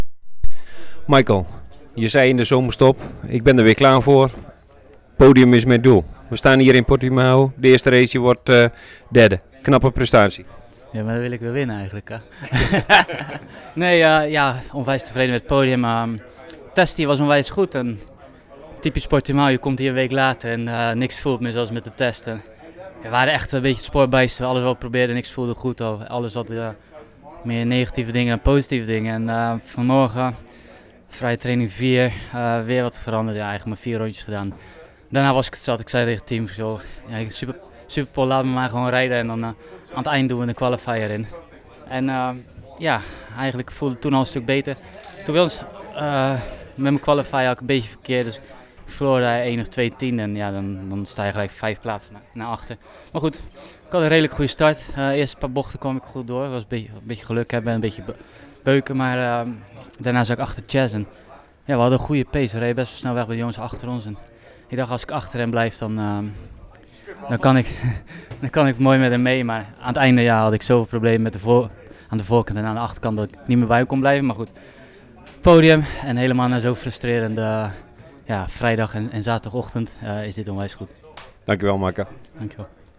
Na afloop van de race zochten we Van der Mark op en vroegen hem om een eerste reactie.